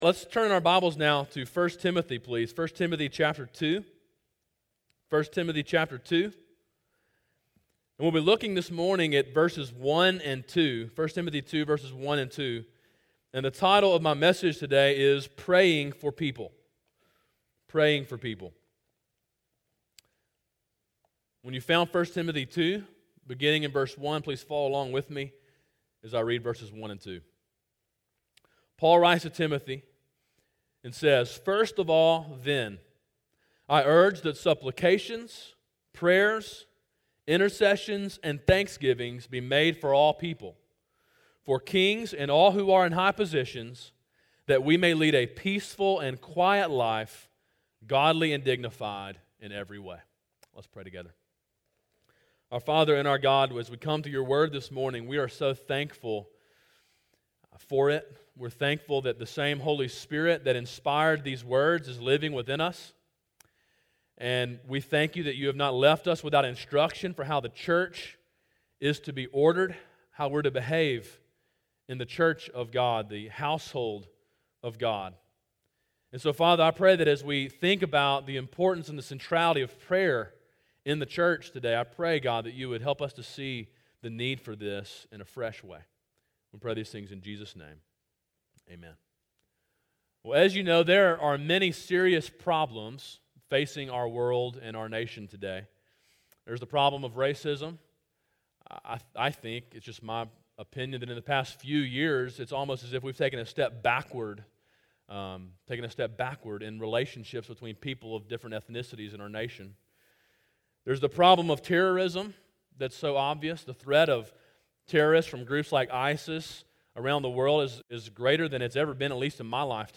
A sermon in a series on 1 Timothy.